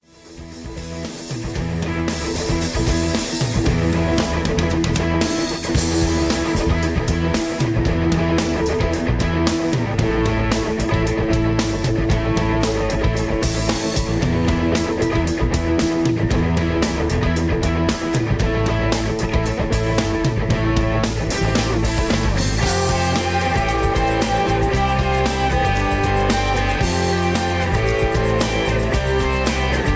Tags: backing tracks, karaoke, sound tracks, rock